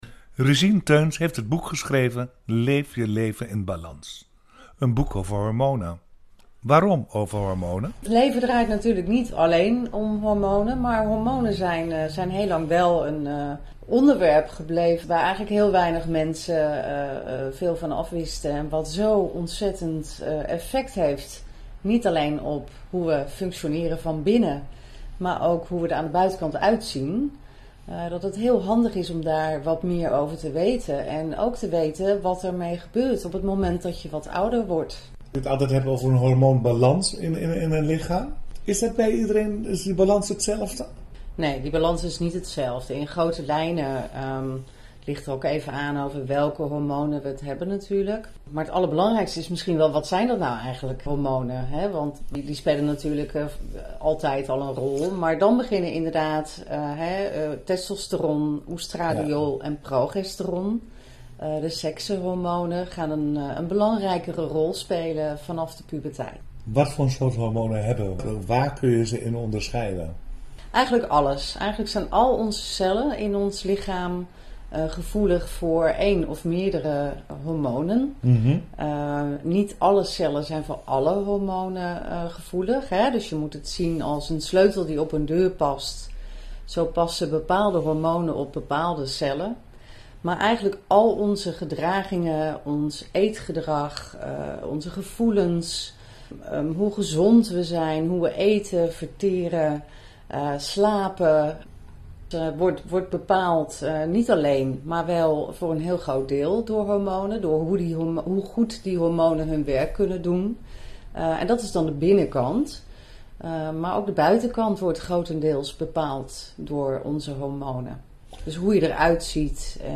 radiointerview-salto-studio45-stadsfm-Amsterdam-over-hormonen.mp3